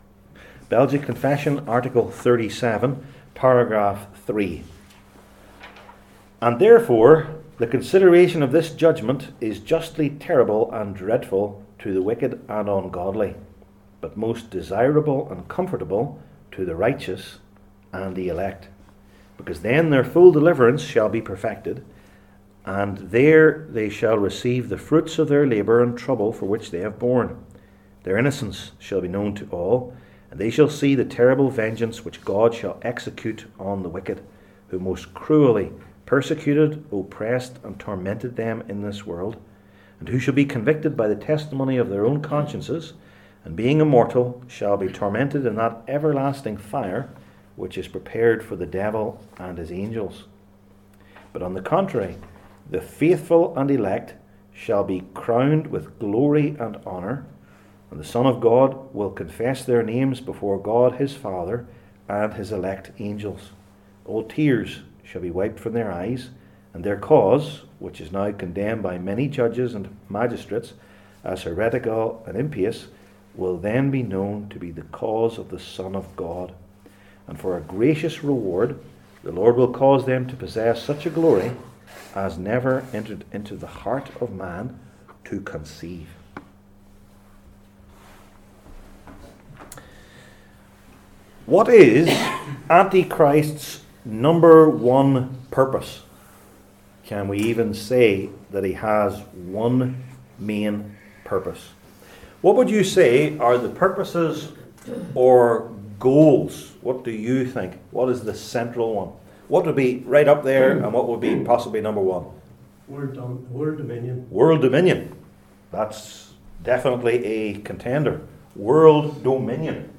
The Last Judgment Passage: Daniel 7:15-28 Service Type: Belgic Confession Classes THE LAST JUDGMENT …